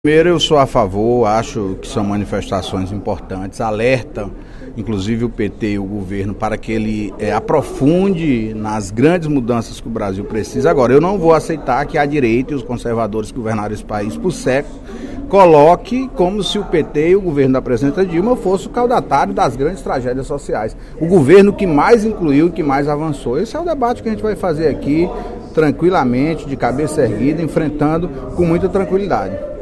No primeiro expediente da sessão plenária desta quinta-feira (20/06), o deputado Antonio Carlos (PT) apoiou as manifestações ocorridas em todo o País nos últimos dias e rebateu o discurso de partidos de oposição sobre insatisfação com o governo do Partido dos Trabalhadores (PT).